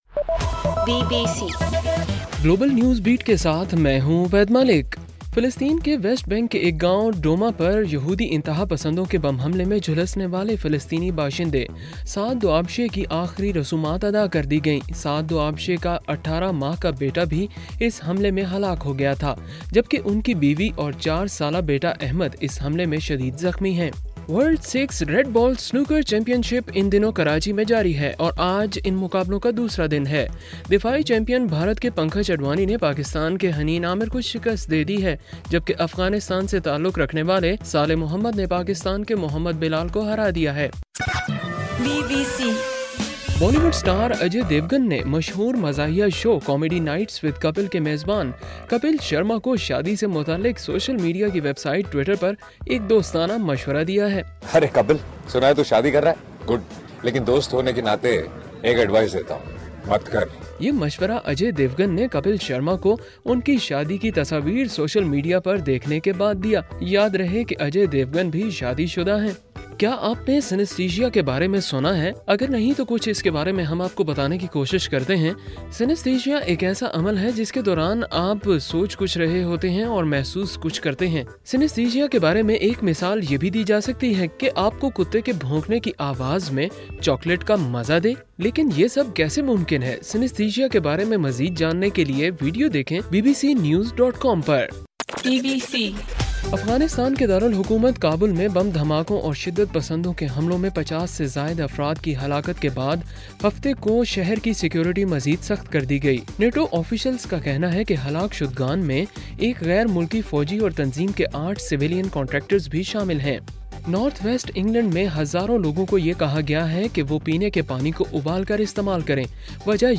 اگست 8: رات 11 بجے کا گلوبل نیوز بیٹ بُلیٹن